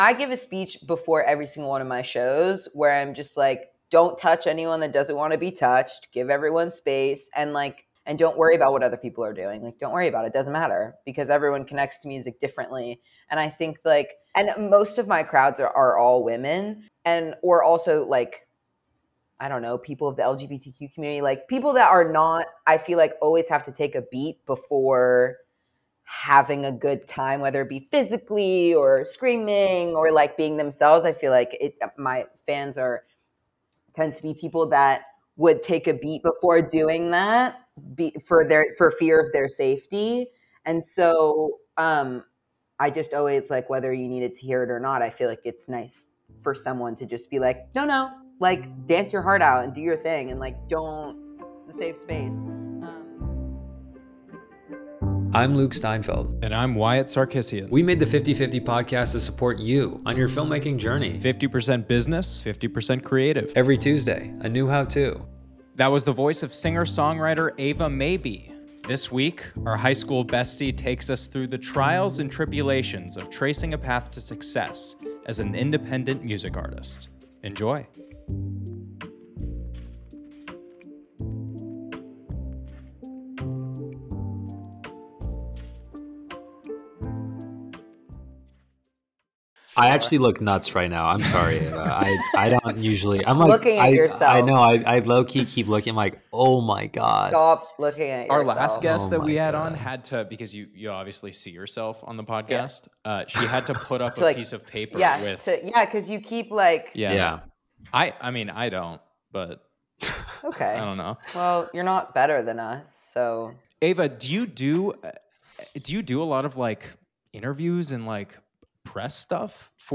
It's a high school reunion this week! The guys chat with singer-songwriter